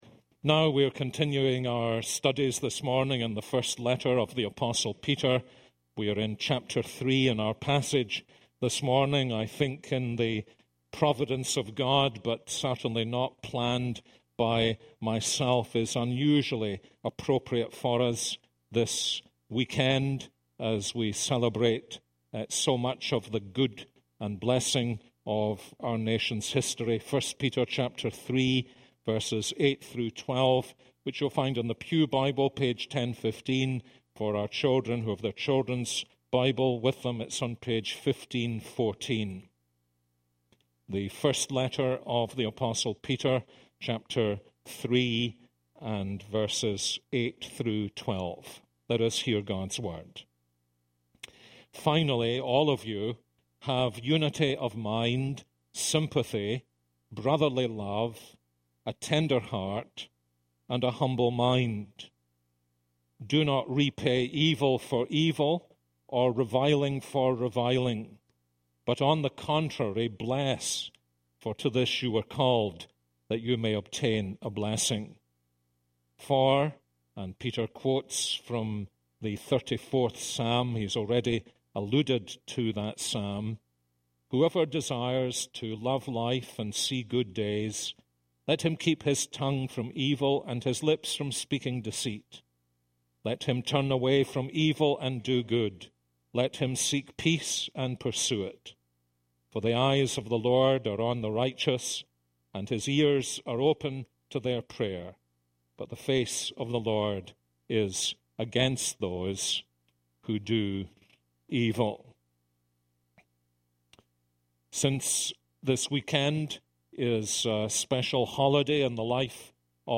This is a sermon on 1 Peter 3:8-12.